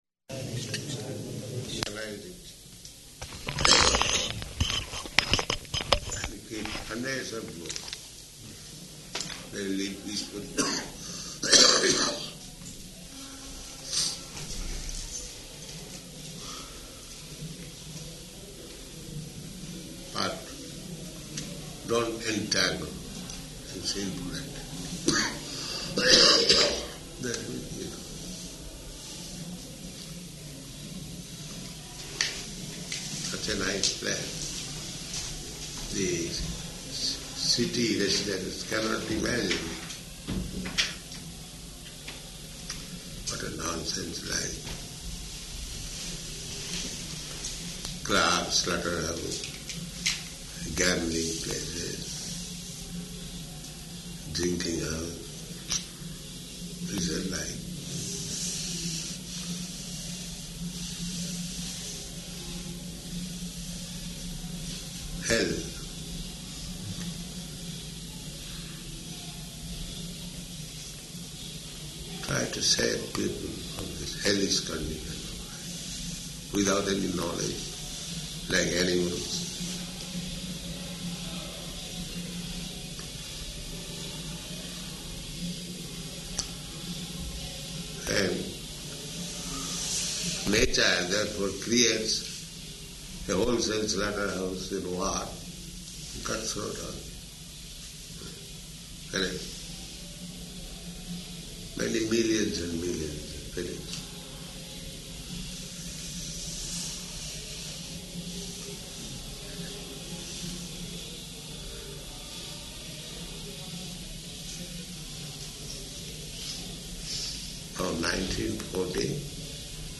Room Conversation
Room Conversation --:-- --:-- Type: Conversation Dated: July 31st 1976 Location: New Māyāpur Audio file: 760731R2.NMR.mp3 Prabhupāda: Organize it and keep hundreds of books.